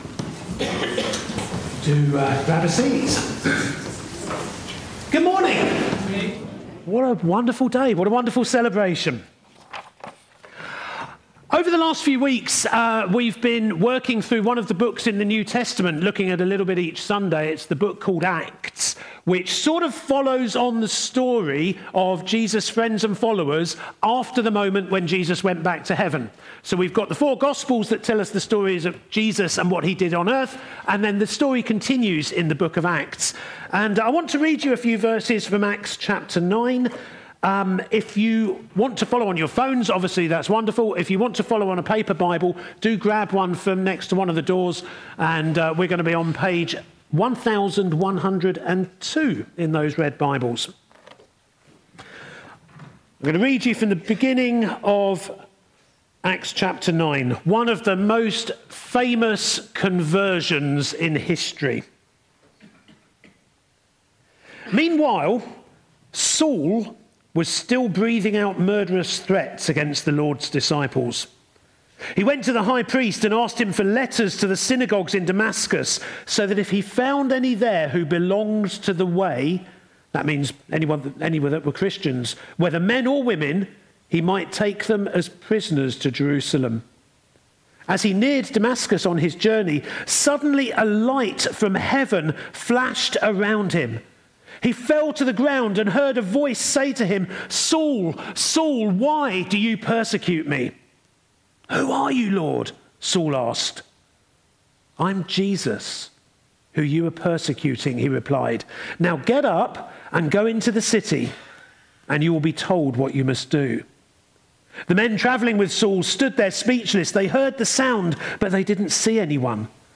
A message from the series "To Boldly Go."